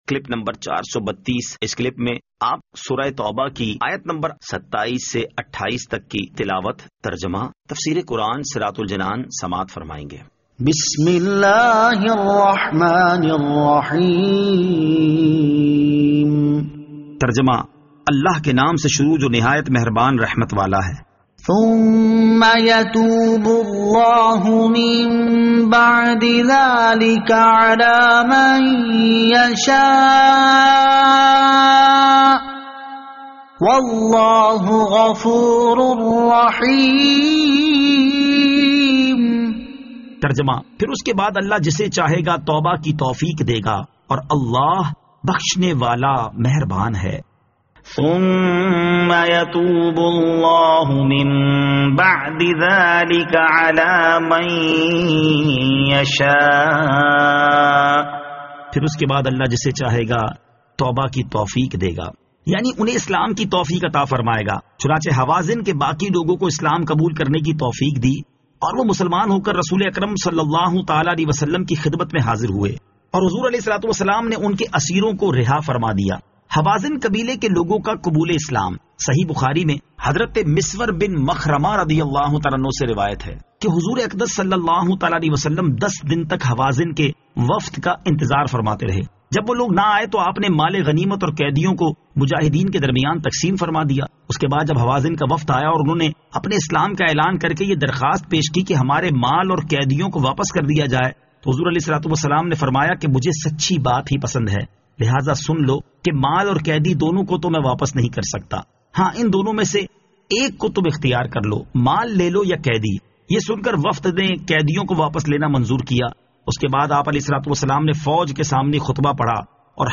Surah At-Tawbah Ayat 27 To 28 Tilawat , Tarjama , Tafseer
2021 MP3 MP4 MP4 Share سُوَّرۃُ التَّوْبَۃ آیت 27 تا 28 تلاوت ، ترجمہ ، تفسیر ۔